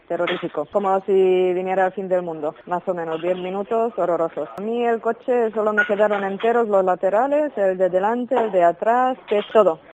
DECLARACIONES DE VECINOS DE LA BISBAL DE L'EMPORDÀ